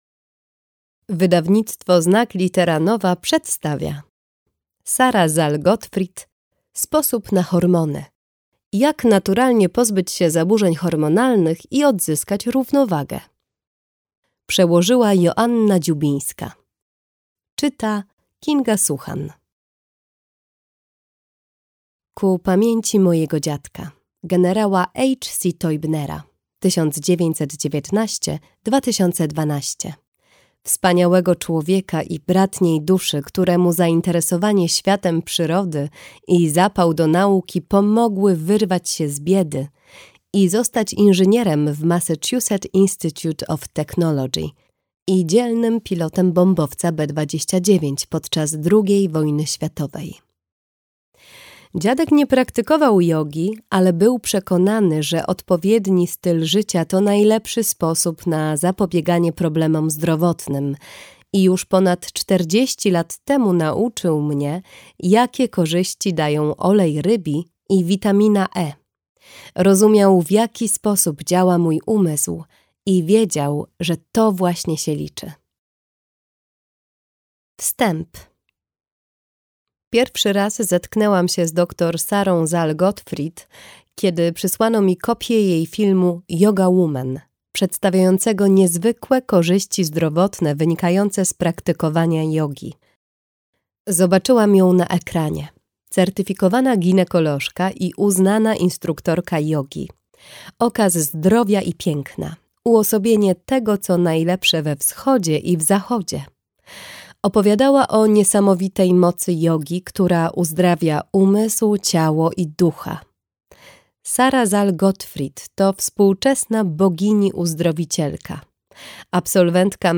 Sposób na hormony. Jak naturalnie pozbyć się zaburzeń hormonalnych i odzyskać równowagę - Gottfried Sara - audiobook